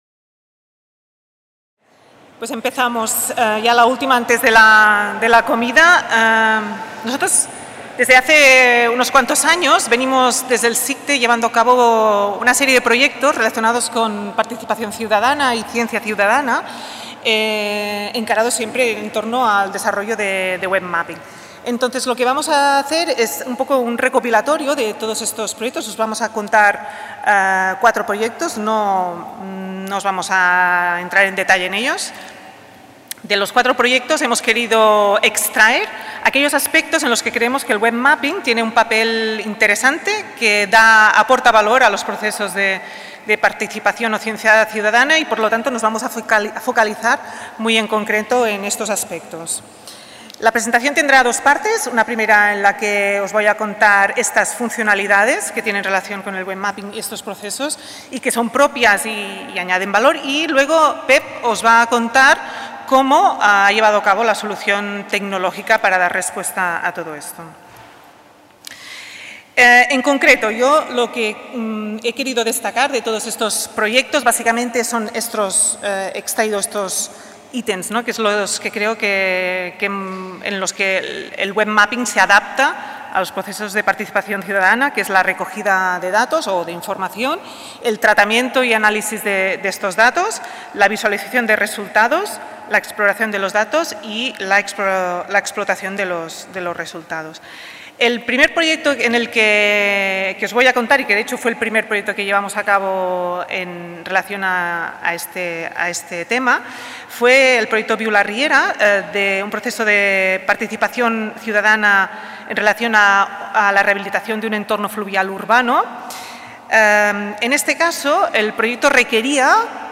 En aquesta conferència